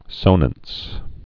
(sōnəns)